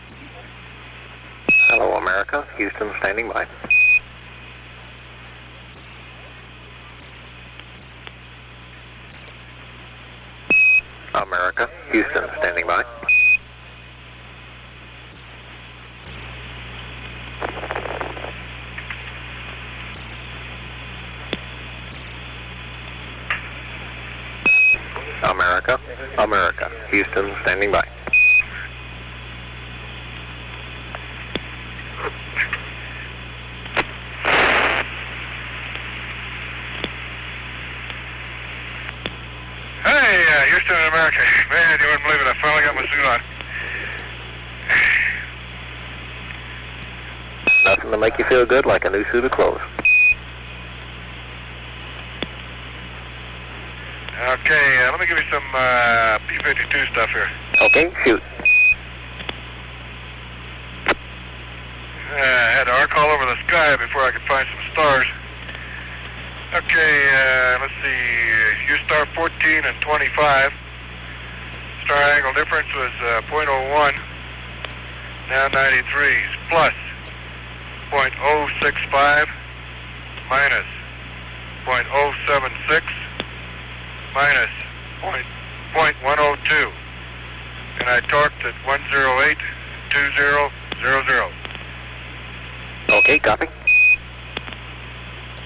It appears that long quiet periods have been deleted, probably by a process of copying from one tape machine to another.